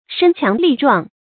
身強力壯 注音： ㄕㄣ ㄑㄧㄤˊ ㄌㄧˋ ㄓㄨㄤˋ 讀音讀法： 意思解釋： 身體強健；精力旺盛。